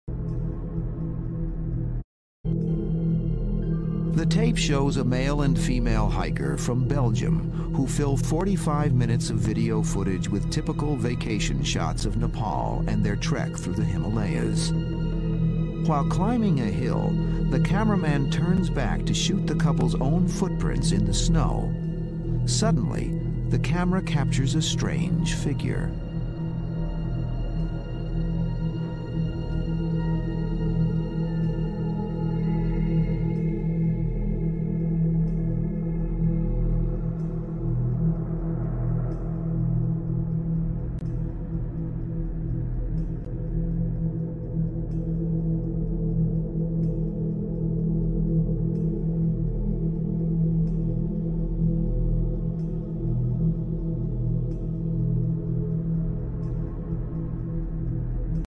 A couple from Belgium was hiking in the Himalayas and recorded what seems to be a sasquatch heading up a mountain.